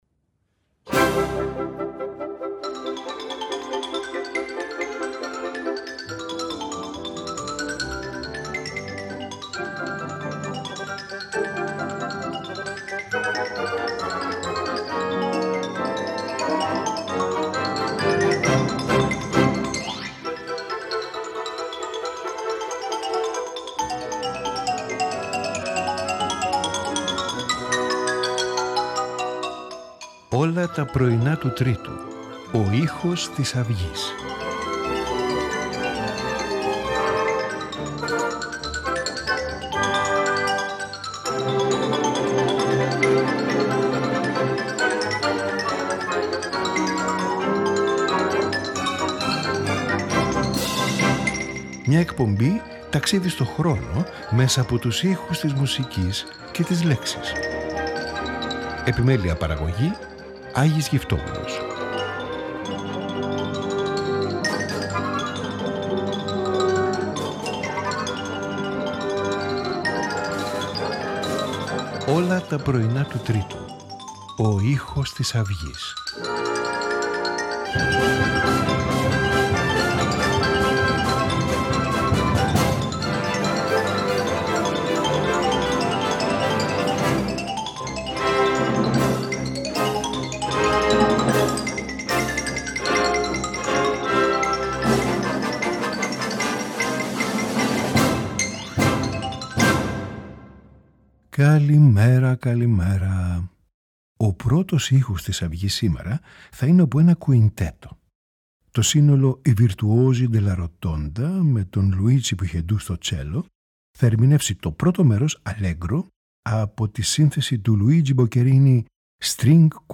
String Quintet No.2 in A minor
Clarinet Quintet in E-flat major
for Cello and Piano
Flute Concerto in F major